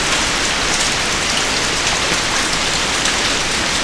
RAIN.WAV